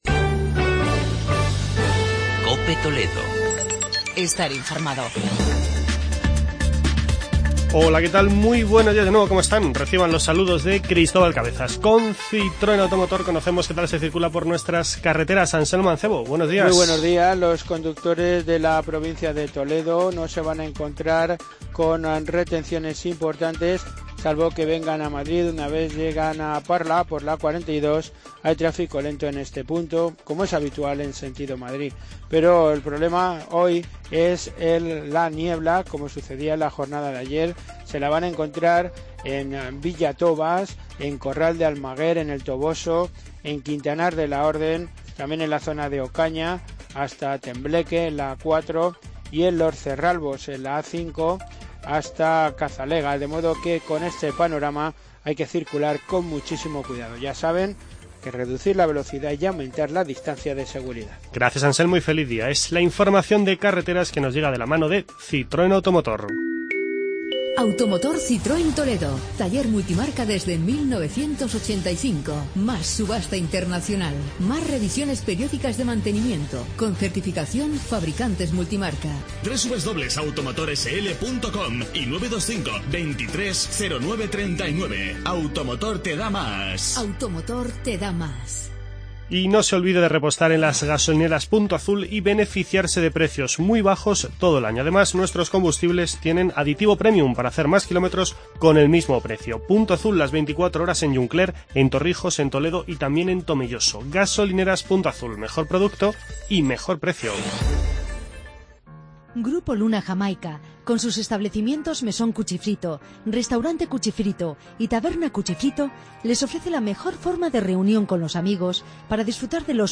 Informativo provincial